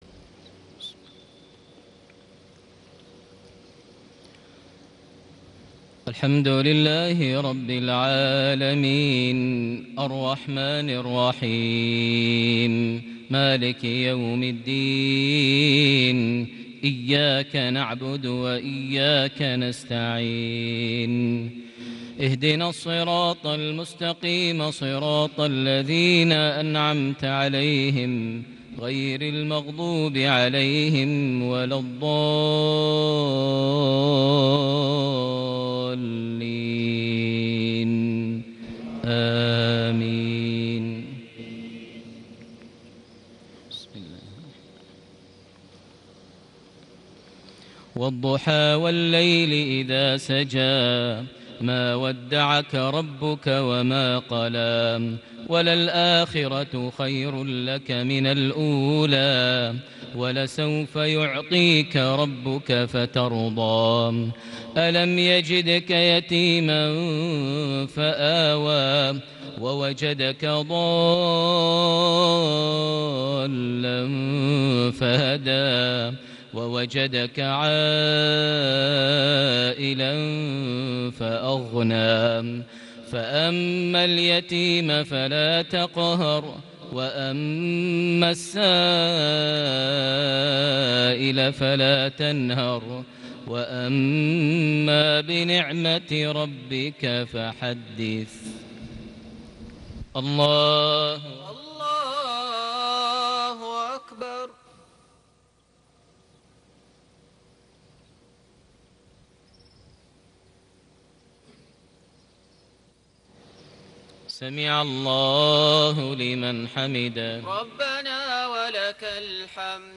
صلاة المغرب ١٩ ذو القعدة ١٤٣٨هـ سورتي الضحى / الشرح > 1438 هـ > الفروض - تلاوات ماهر المعيقلي